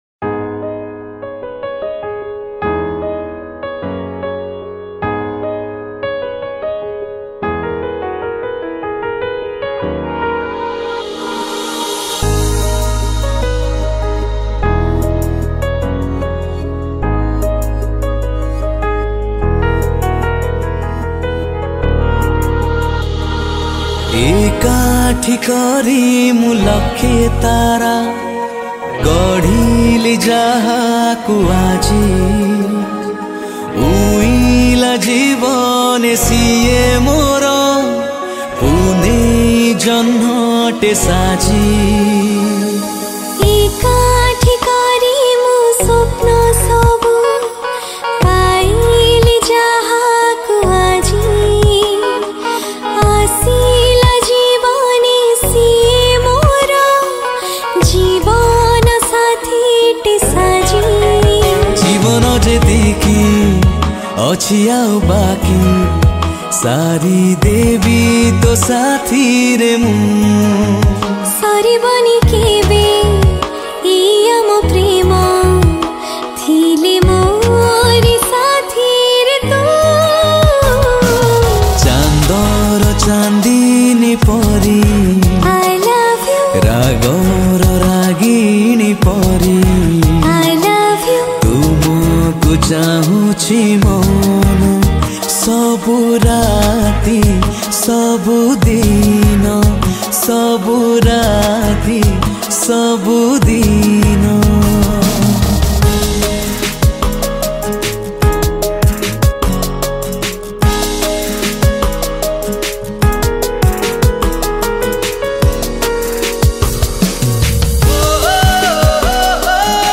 Music Recreated
Guitar